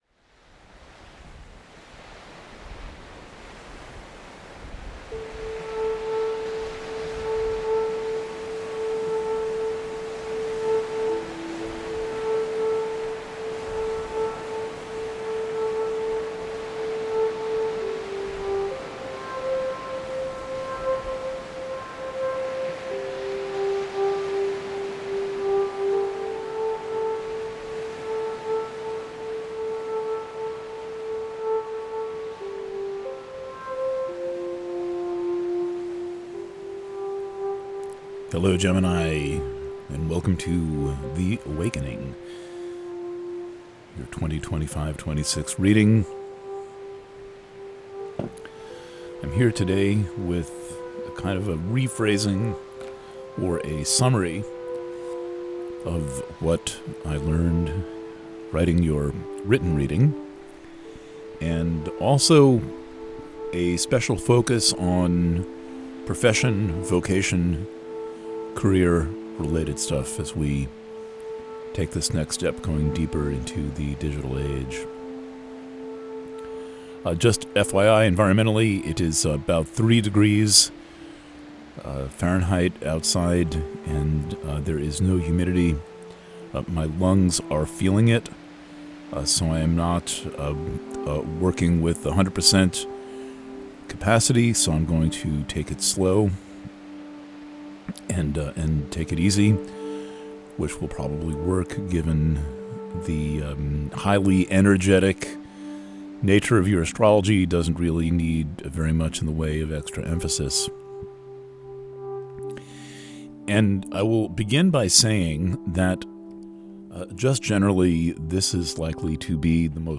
Preview – The Awakening for Gemini Purchasing options for The Awakening Preview – Written reading Preview – The Awakening for Gemini – PDF Preview – Audio reading Alternate Player (Audio Only) Views: 90